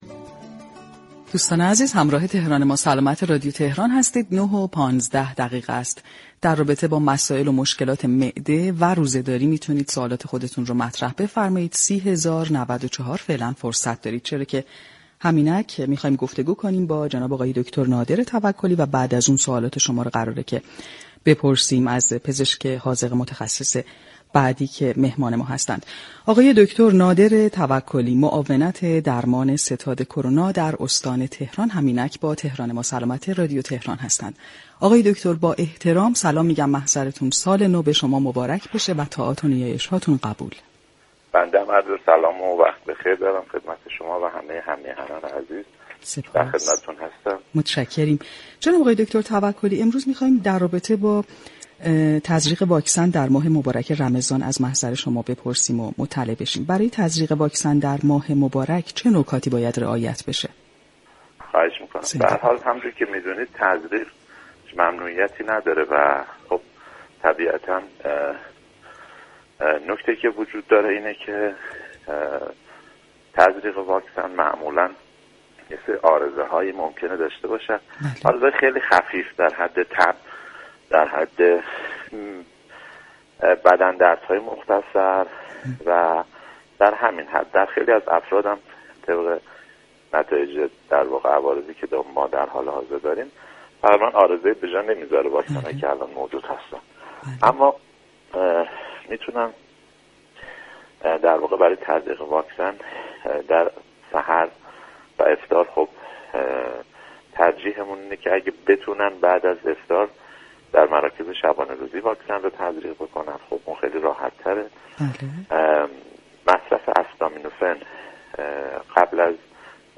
به گزارش پایگاه اطلاع رسانی رادیو تهران، دكتر نادر توكلی معاون درمان ستاد كرونای استان تهران در گفتگو با برنامه تهران ما سلامت رادیو تهران درباره نكاتی كه باید برای تزریق واكسن كرونا در ماه مبارك رمضضان رعایت شود گفت: تزریق واكسن هیچ ممنوعیتی در این ماه ندارد.